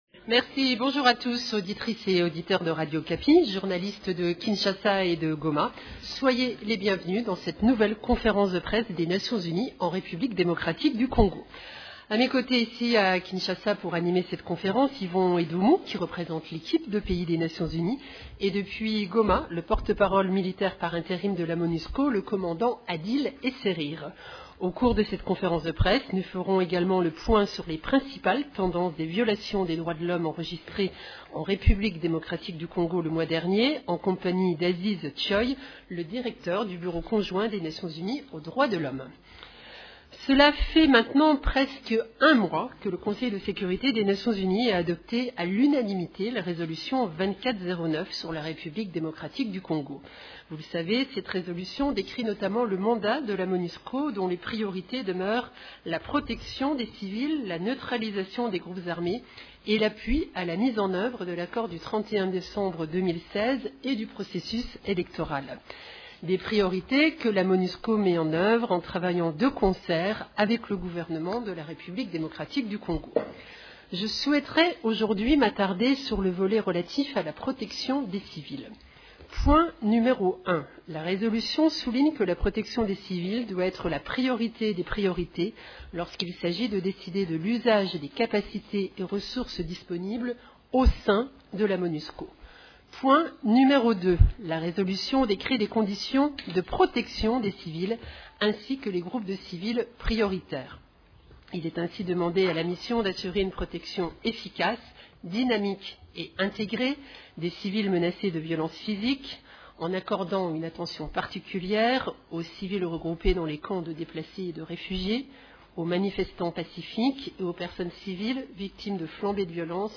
Conférence de presse du jeudi 25 avril 2018
La conférence de presse hebdomadaire de l’ONU du mercredi 25 avril à Kinshasa et à Goma a porté sur les activités d’agences des Nations unies en RDC.
Ecoutez la première partie de cette conférence de presse: